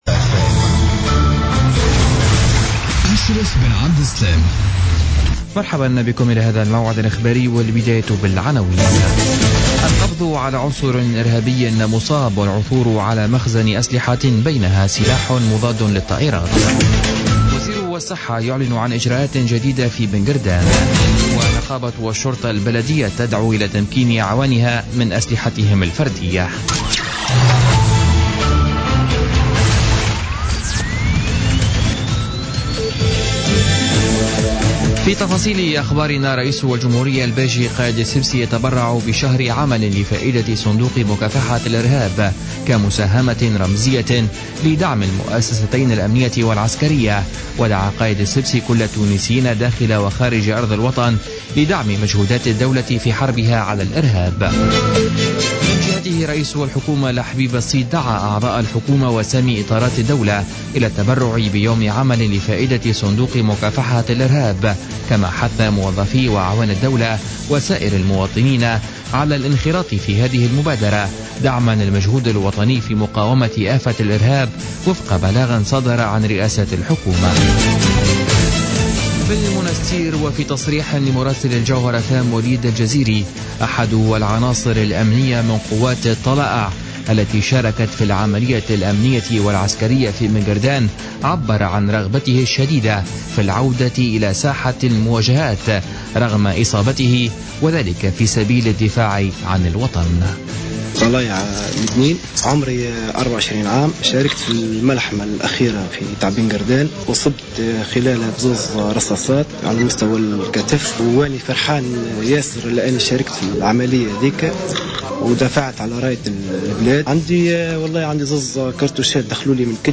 نشرة أخبار السابعة مساء ليوم السبت 12 مارس 2016